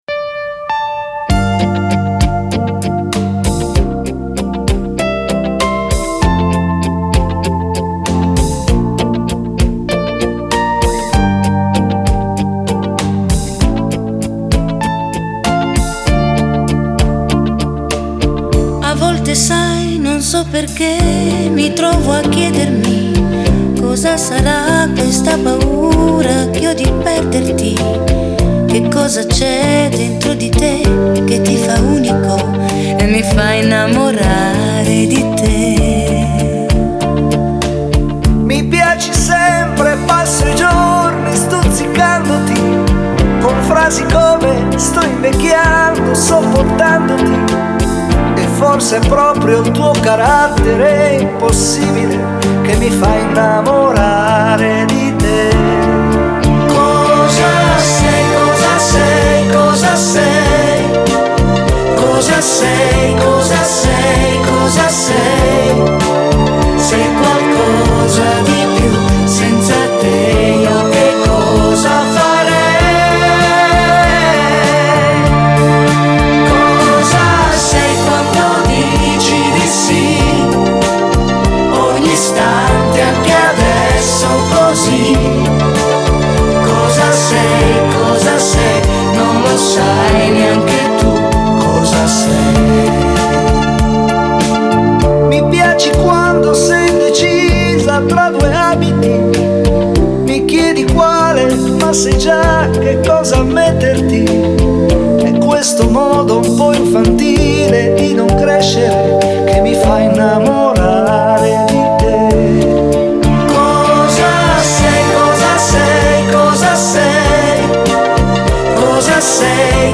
• Категория:Лучшие мировые баллады